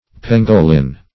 pengolin - definition of pengolin - synonyms, pronunciation, spelling from Free Dictionary Search Result for " pengolin" : The Collaborative International Dictionary of English v.0.48: Pengolin \Pen"go*lin\, n. (Zool.)